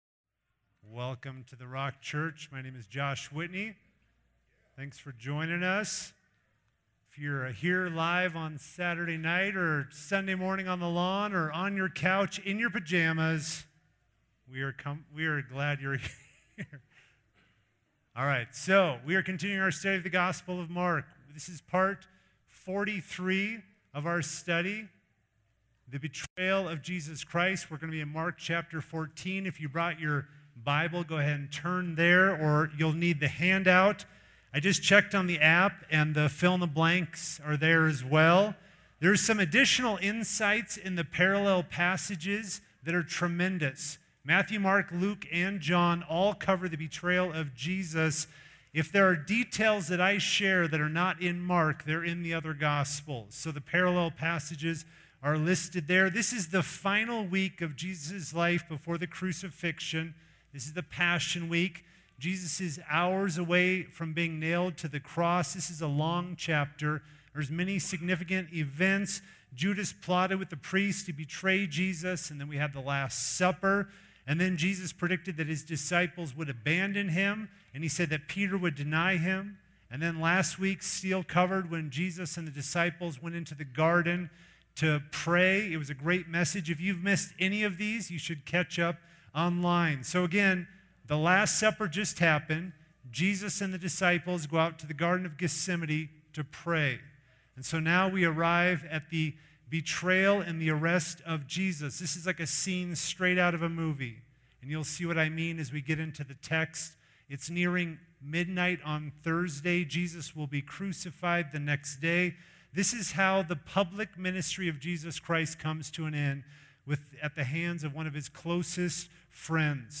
Recent Messages - The Rock Church